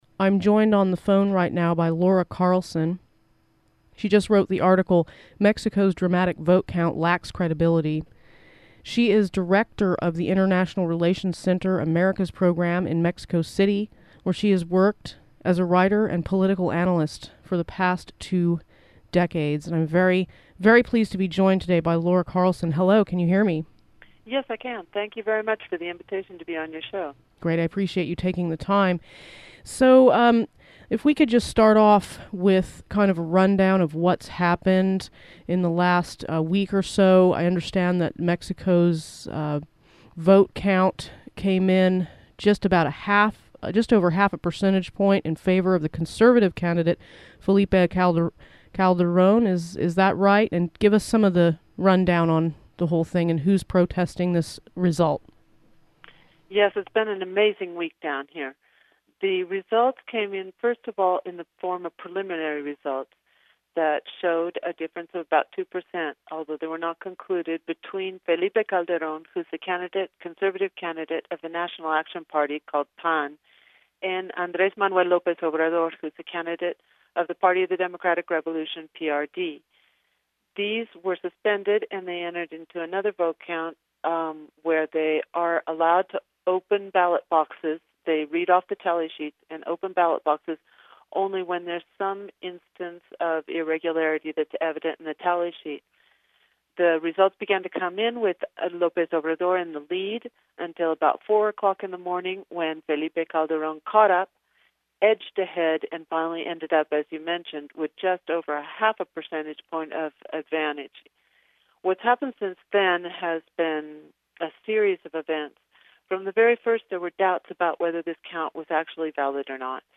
Free Radio Santa Cruz 101.1 fm interviews